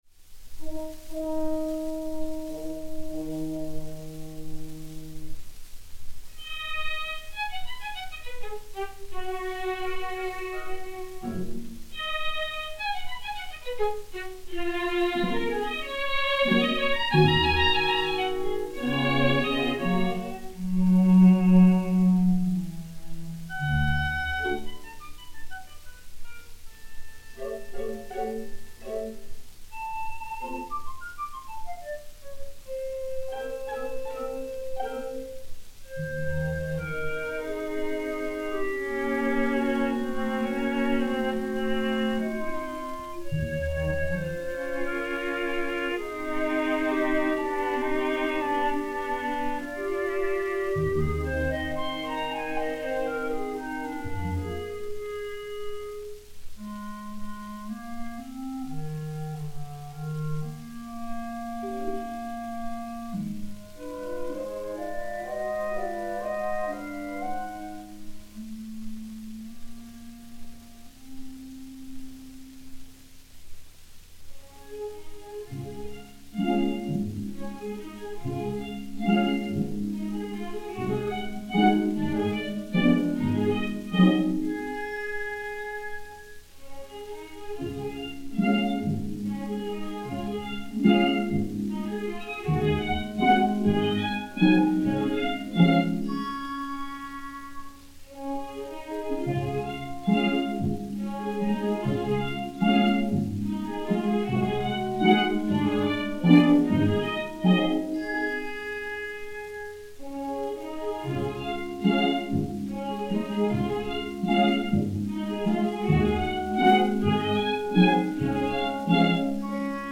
Orchestre Symphonique